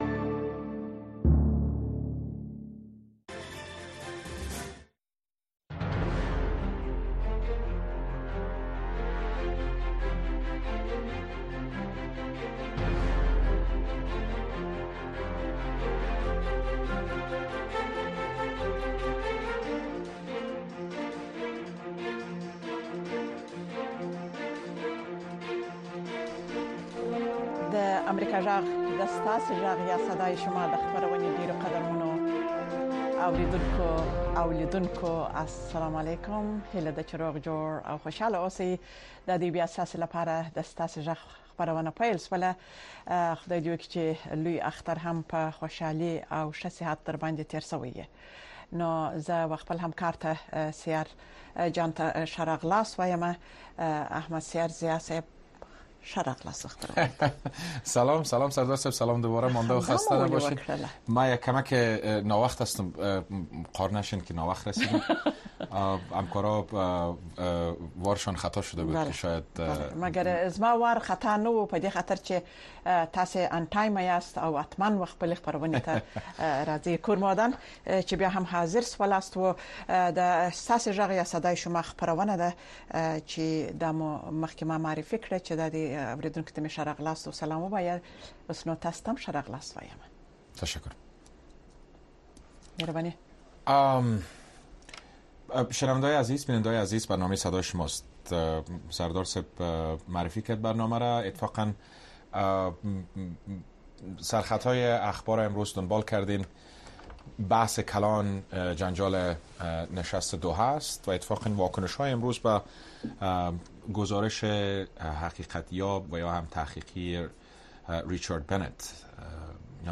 ستاسې غږ خپرونه کې د امریکا غږ د اشنا رادیو اوریدونکي په مستقیمه توګه له مونږ سره اړیکه نیسي او د خپرونې د چلوونکو او اوریدونکو سره خپل نظر، اندیښنې او شکایتونه شریکوي. دا خپرونه په ژوندۍ بڼه د افغانستان په وخت د شپې د ۹:۳۰ تر ۱۰:۳۰ بجو پورې خپریږي.